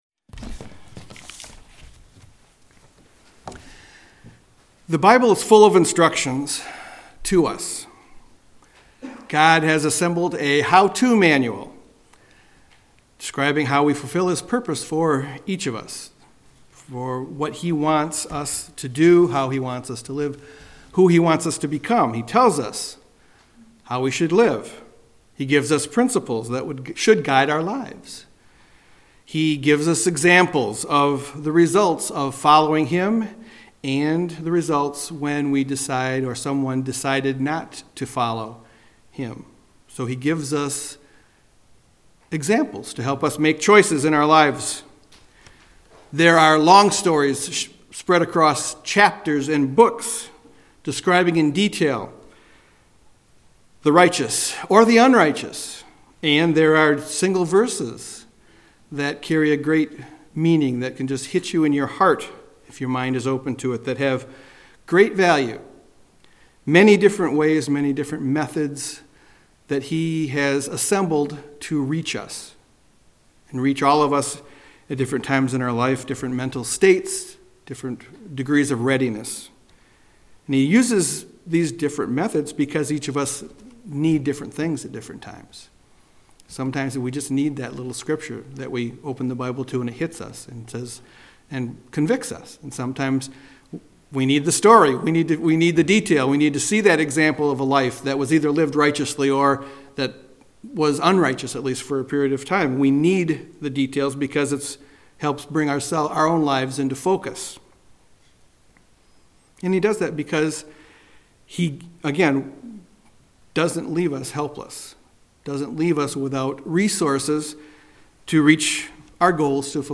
When we think of preparing for the end times, we need to look at what God expects of us as His people. In this sermon we will look at three things that can help us to learn better how to prepare for the coming of Christ.
Given in Grand Rapids, MI